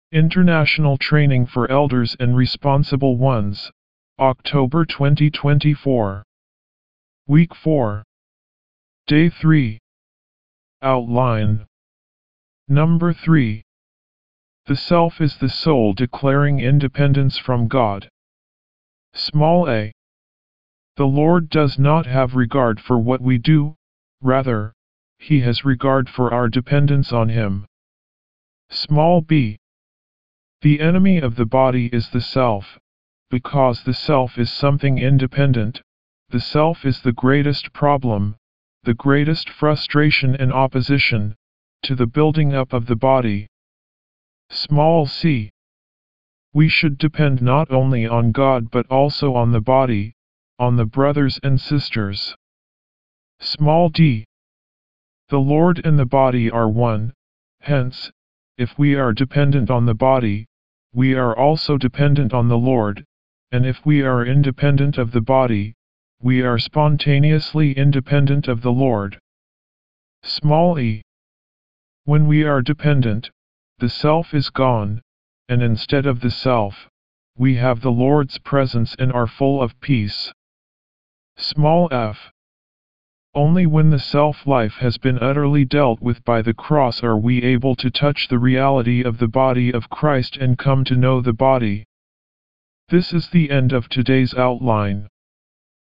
W4  Outline Recite
D3 English Rcite：